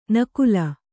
♪ nakula